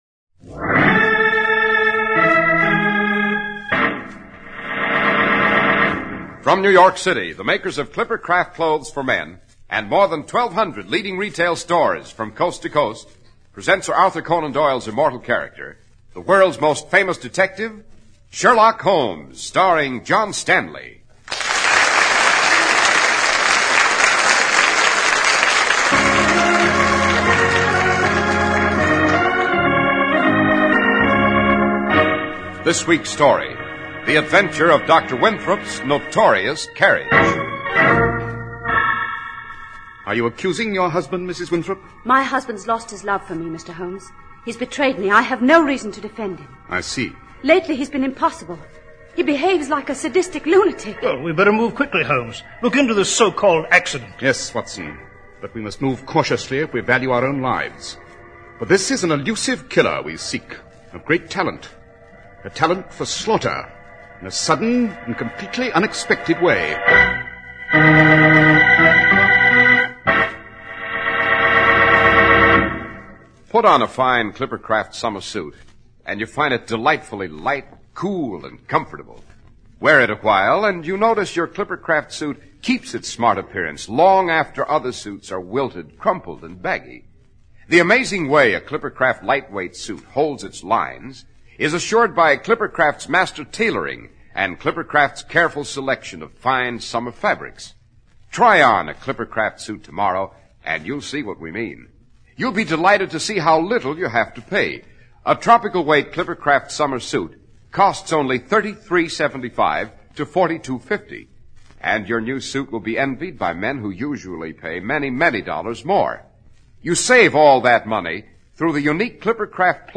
Radio Show Drama with Sherlock Holmes - Dr Winthrops Notorious Carriage 1949